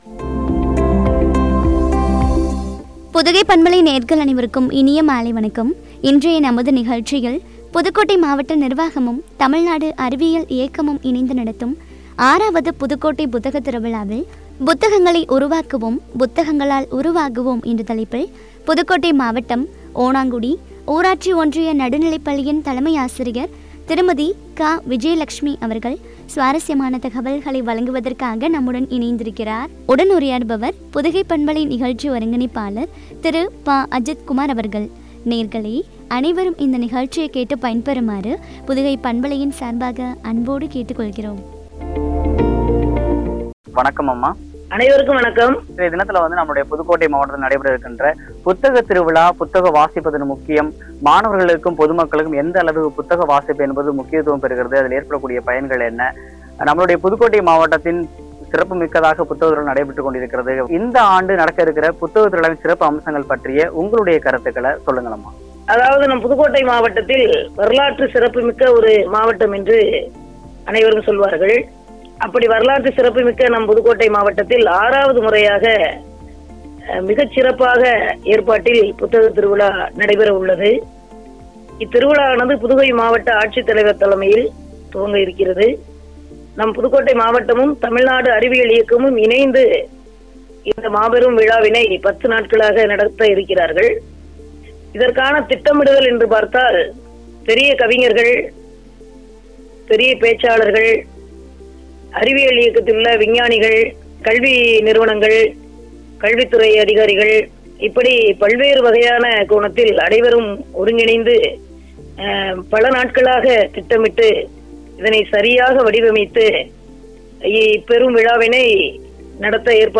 குறித்து வழங்கிய உரையாடல்.